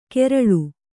♪ keraḷu